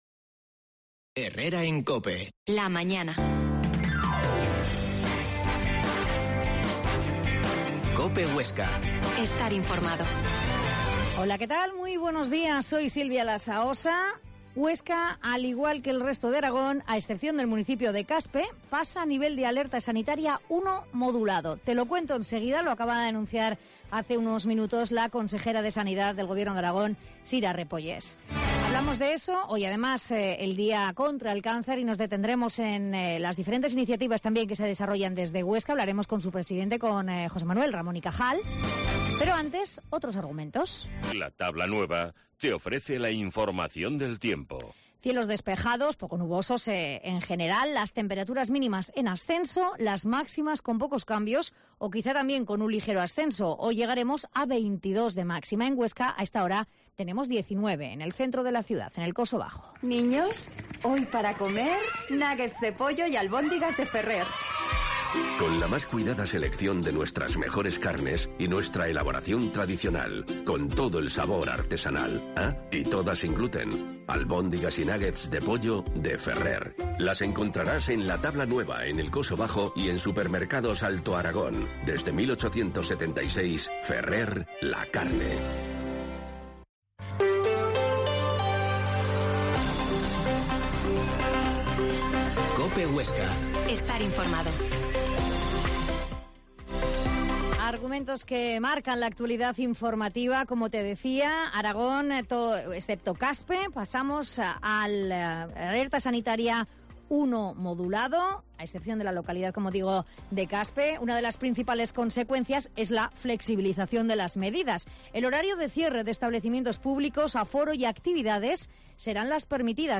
La Mañana en COPE Huesca - Informativo local Herrera en Cope Huesca 12,50h.